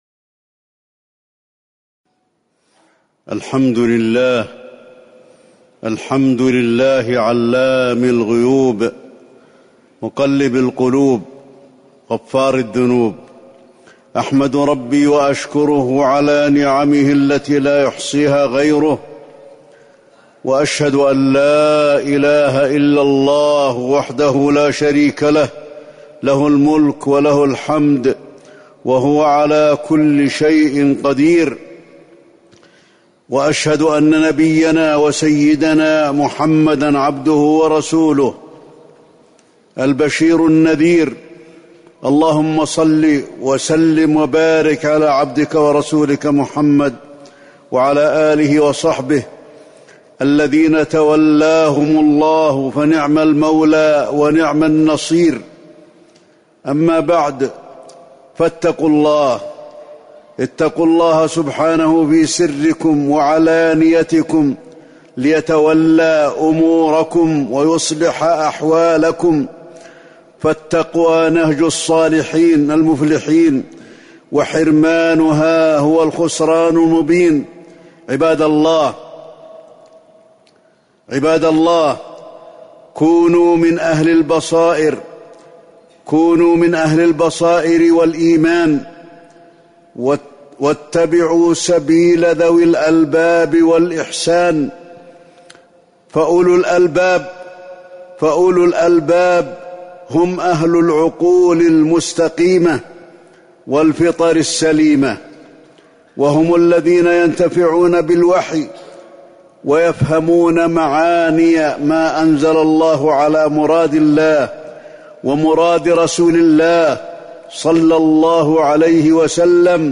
تاريخ النشر ١٣ جمادى الآخرة ١٤٤١ هـ المكان: المسجد النبوي الشيخ: فضيلة الشيخ د. علي بن عبدالرحمن الحذيفي فضيلة الشيخ د. علي بن عبدالرحمن الحذيفي الاعتبار والتفكر في خلق الله